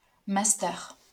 En France, le diplôme national de master, abrévié master ([ma.stœr]